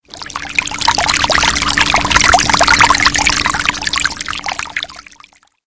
Dzwonki na telefon Water Sound
Kategorie Efekty Dźwiękowe